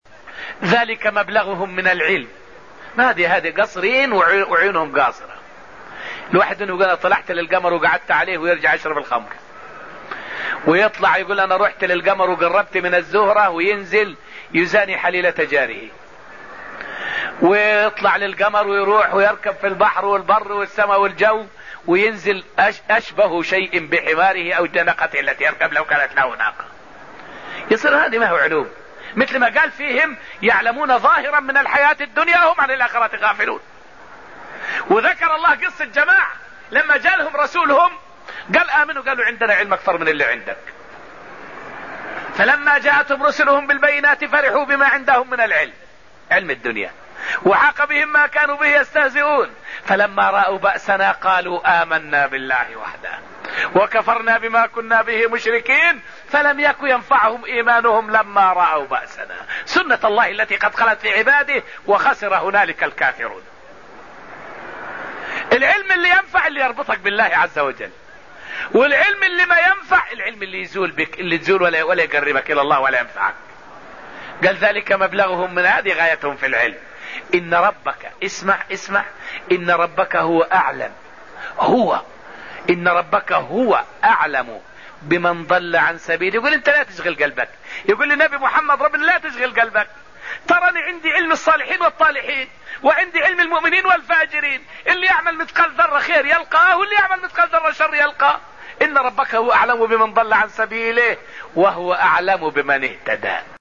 فائدة من الدرس العاشر من دروس تفسير سورة النجم والتي ألقيت في المسجد النبوي الشريف حول العلم الذي ينفع والعلم الذي لا ينفع.